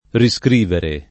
vai all'elenco alfabetico delle voci ingrandisci il carattere 100% rimpicciolisci il carattere stampa invia tramite posta elettronica codividi su Facebook riscrivere [ ri S kr & vere ] v.; riscrivo [ ri S kr & vo ] — coniug. come scrivere